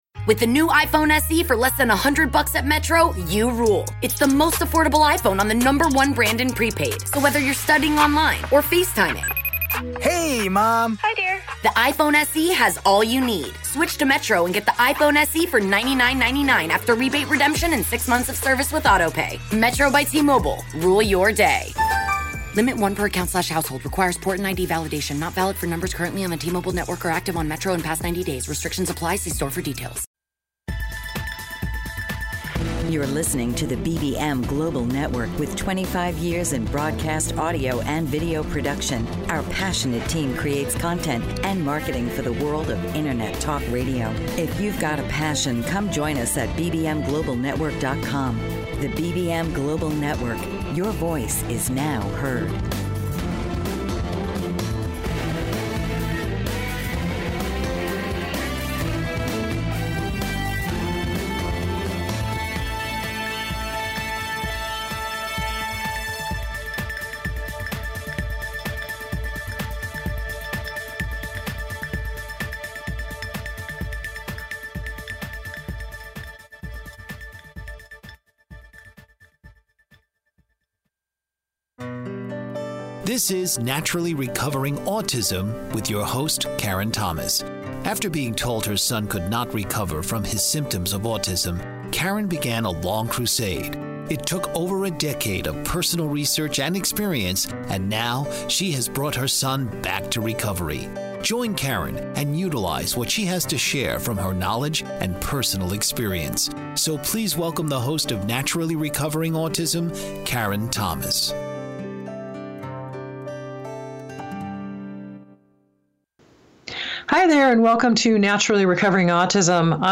Radio Show Interview
EMDR-Therapy-Radio-Interview.mp3